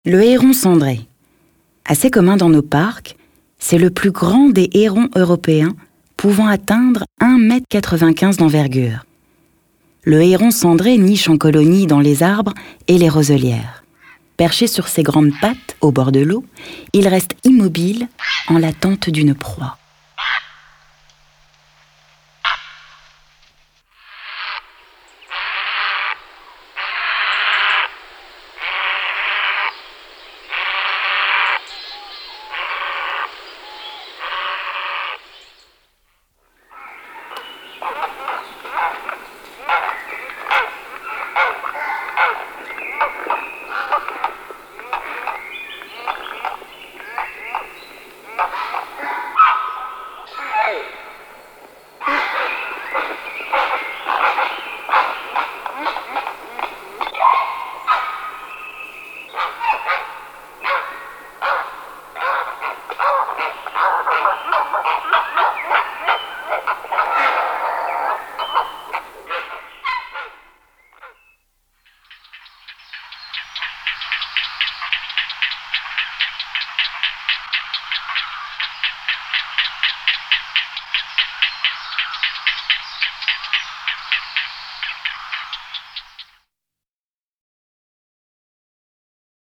Index of / stock ancien/6/09_le_carnaval/sons oiseaux
heron.mp3